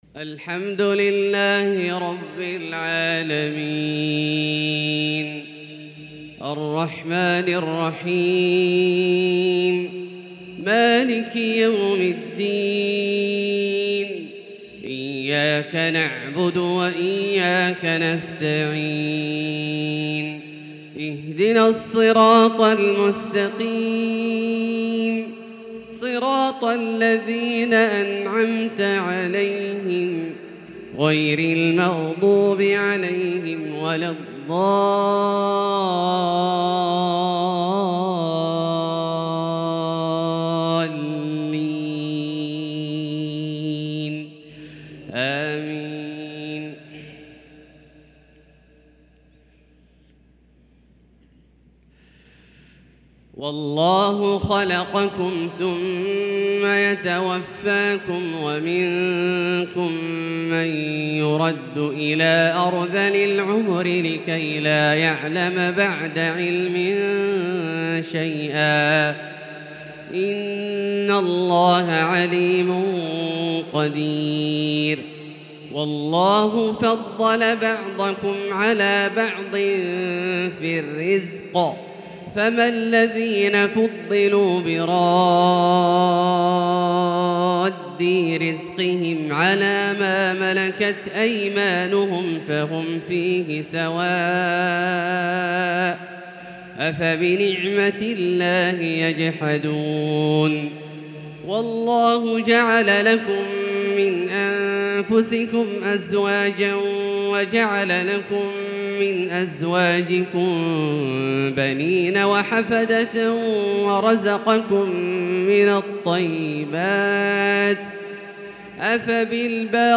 فجر الأربعاء 8-7-1443هـ من سورة النحل | Fajr prayer from Surat An-Nahl 9-2-2022 > 1443 🕋 > الفروض - تلاوات الحرمين